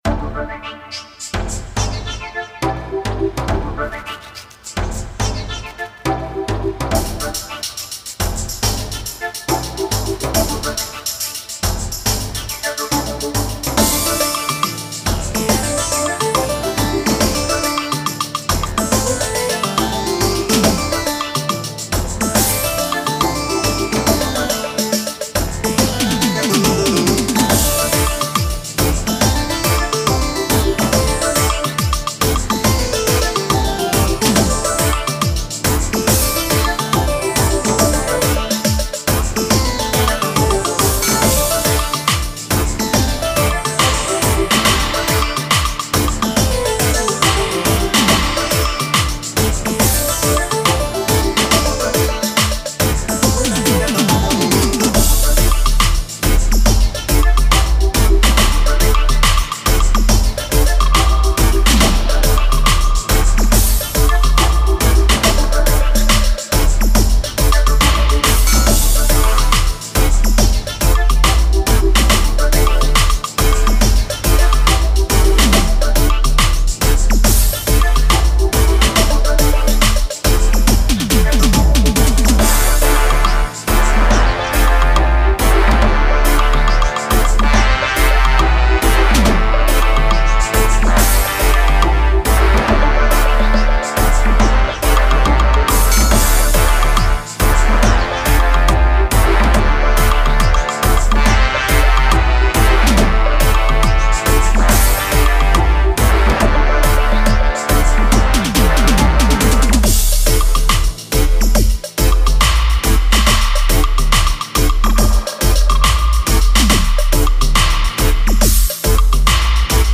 FRESH DUB